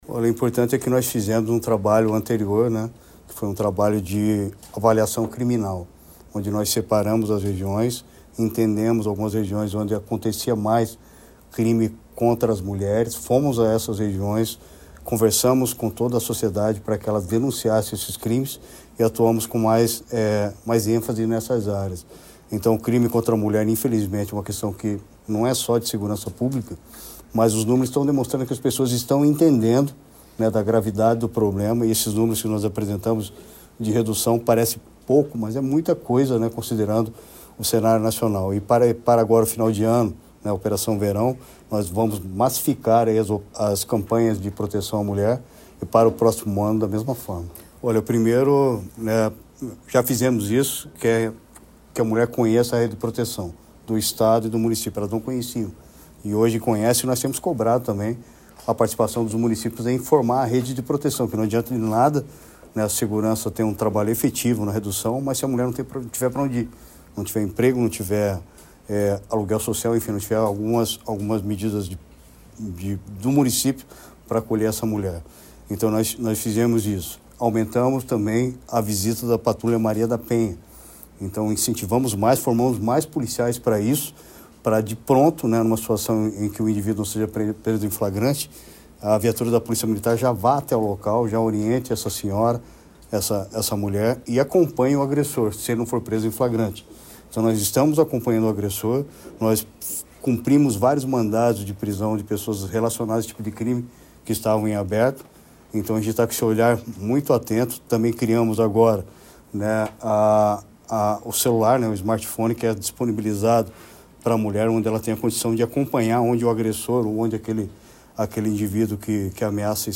Sonora do secretário da Segurança Pública, Hudson Leôncio Teixeira, sobre o Paraná ter reduzido os número de feminicídios e estupros em 2025